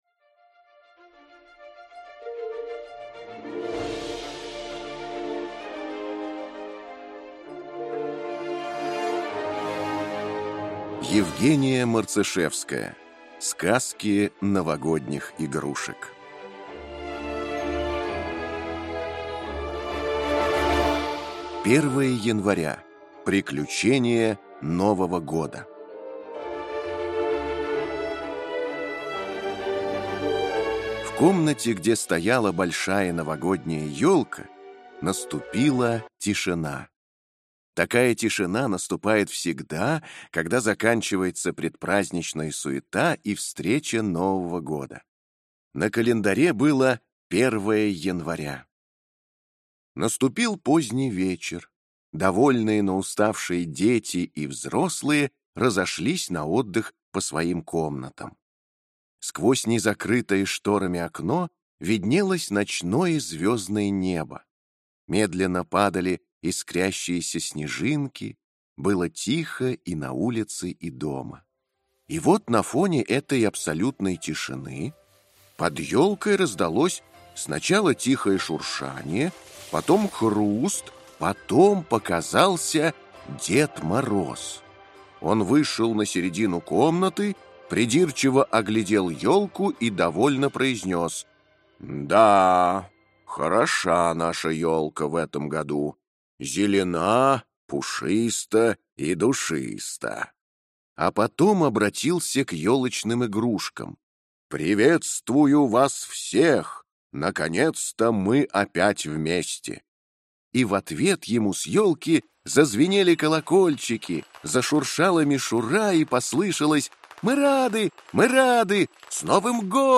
Аудиокнига Сказки новогодних игрушек | Библиотека аудиокниг